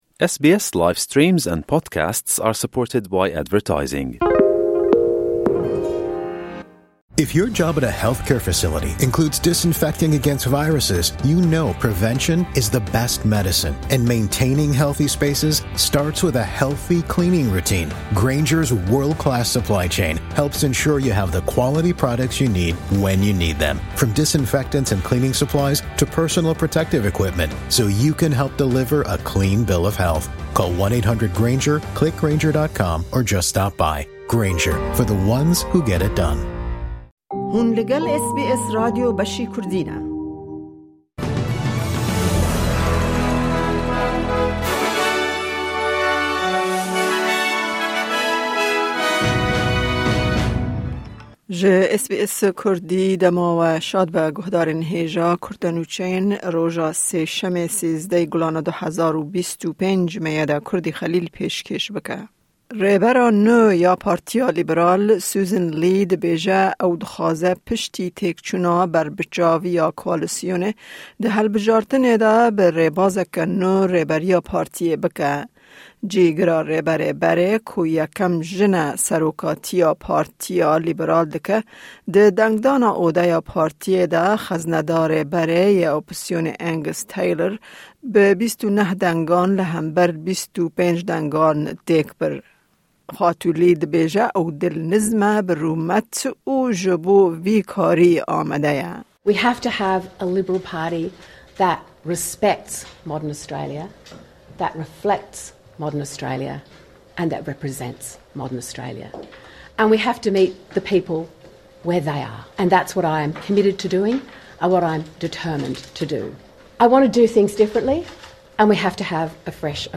kurte Nûçeyên roja Sêşemê 13î Gulana 2025